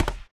Add footstep sounds
stepstone_5.wav